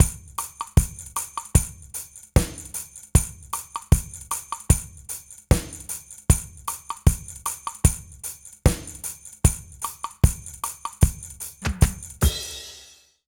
British REGGAE Loop 078BPM.wav